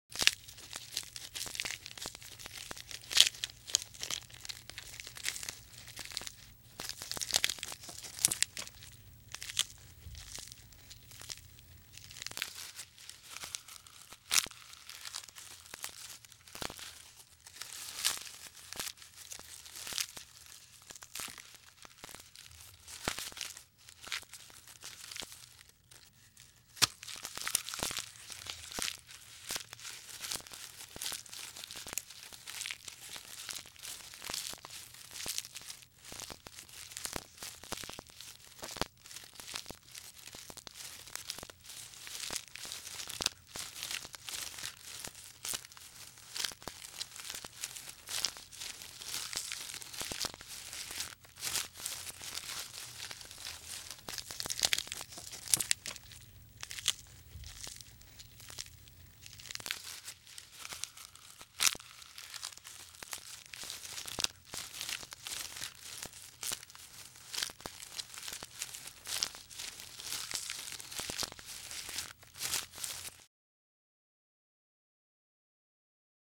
animal
Giraffe Eating and Munching